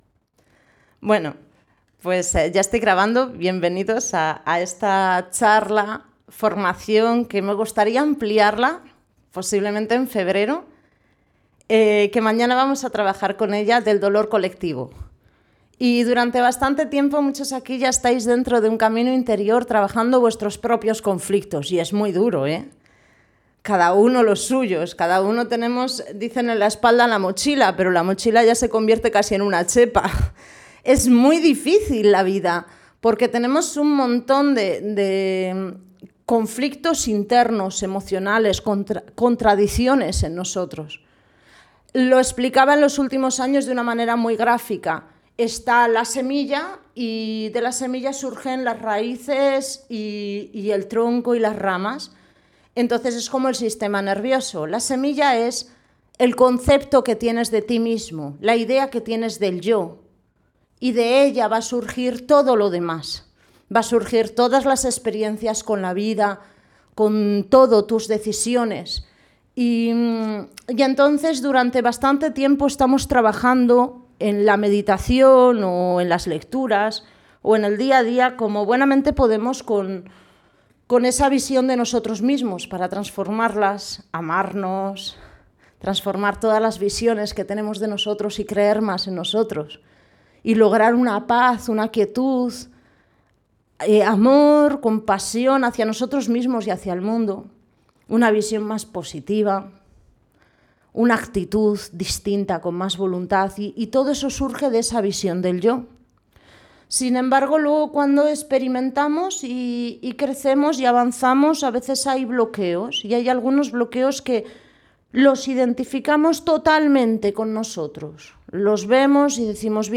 Meditación-charla abrazando el dolor. Enfocado a heridas y conflictos familiares